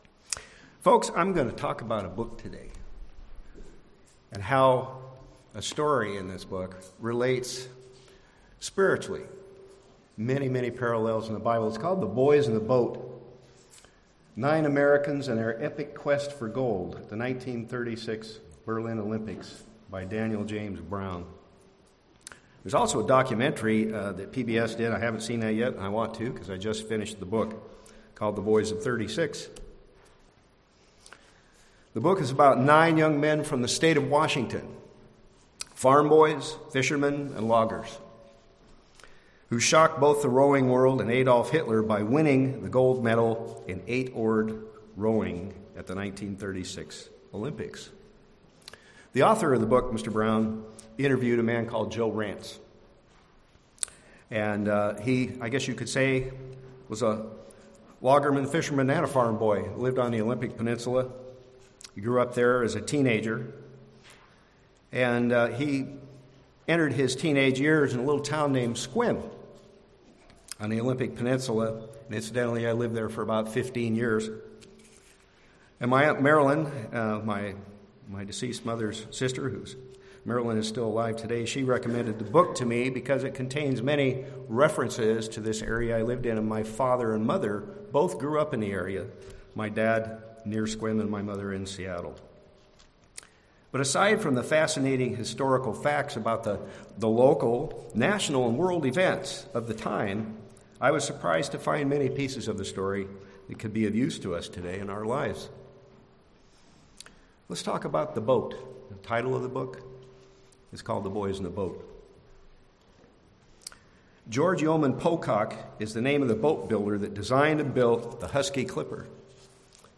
Listen to this sermon to learn about the amazing spiritual parallels in the Bible to what a crew of eight rowers and their coxswain (steers the boat) in a handcrafted rowing shell experienced when they grew up during the depression, and went on to win a gold medal at the Berlin Olympics in 1936.